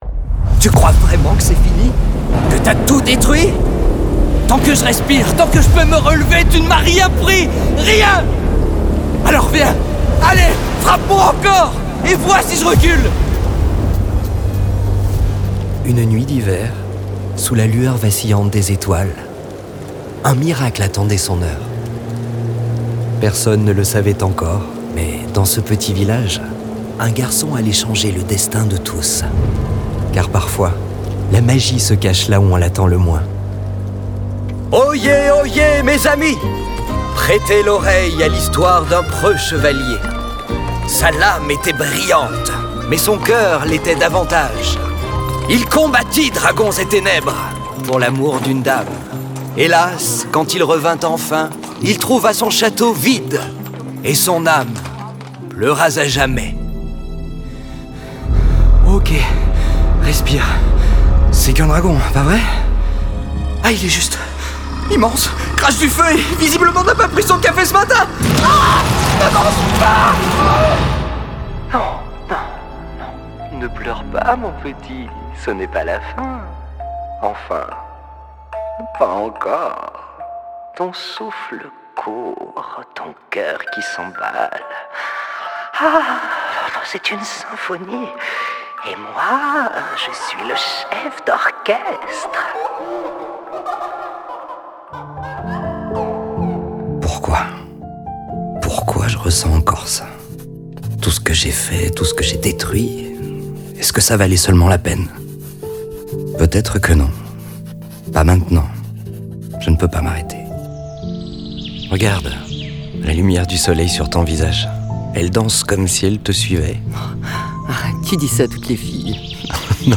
French voice over
Natural, Accessible, Friendly